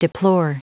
Prononciation du mot deplore en anglais (fichier audio)
Prononciation du mot : deplore